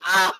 Gasp.ogg